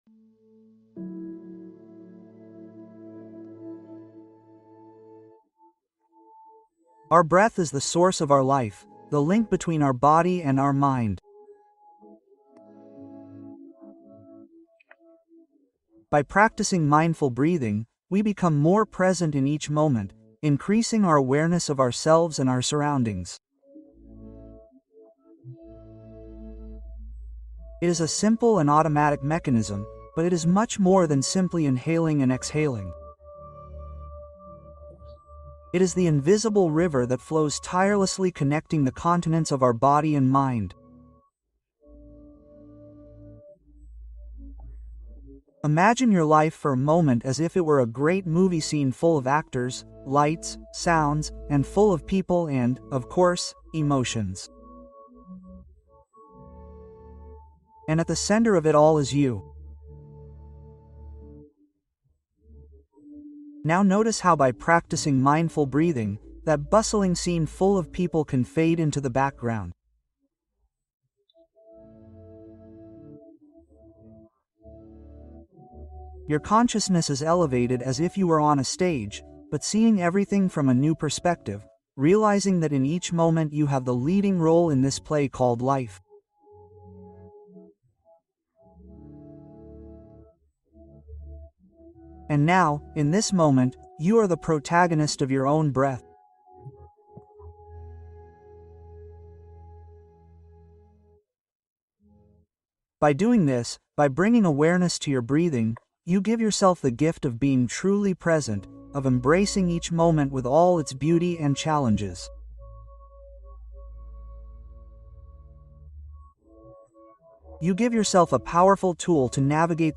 Relajación Nocturna Guiada: Preparación Mental para el Descanso